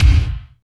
35.01 KICK.wav